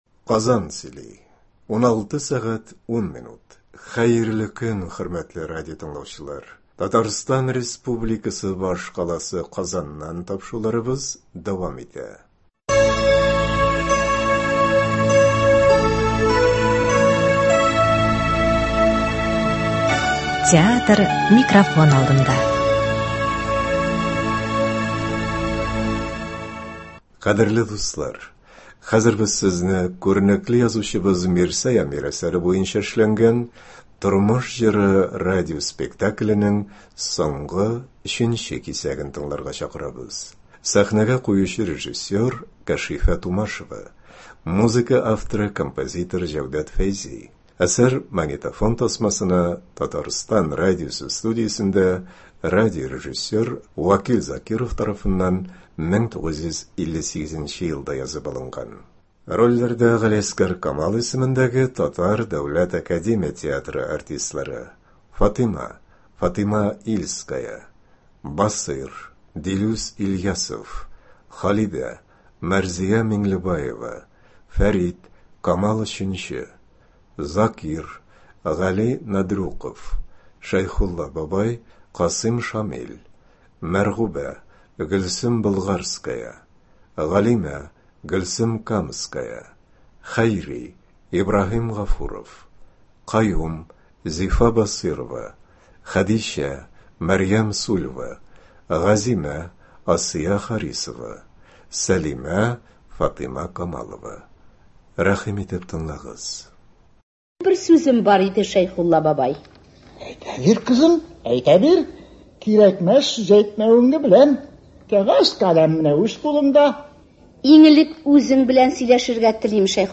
“Тормыш җыры”. Радиоспектакль.
Бу язма безнең өчен шул ягы белән кадерле, анда татар театры тарихында якты йолдыз булып балкыган бик күп мәшһүр артистларның тавышлары сакланып калган.